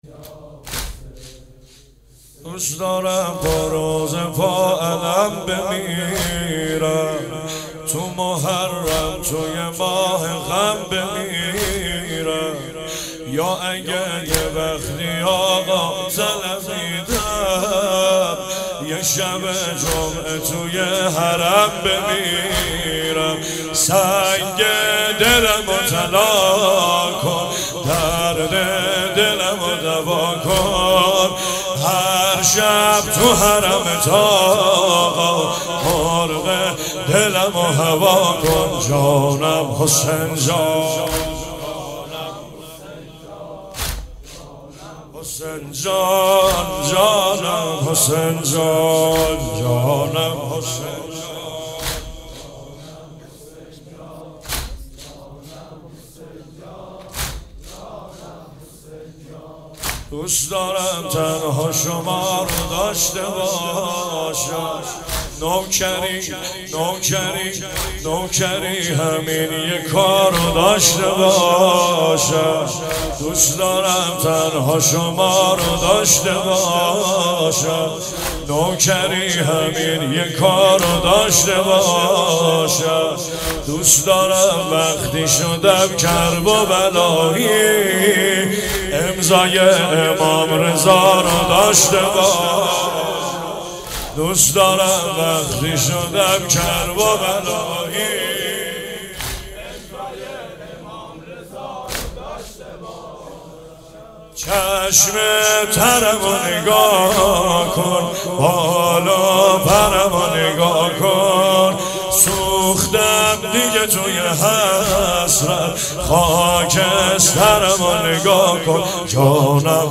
مناسبت : شب هشتم محرم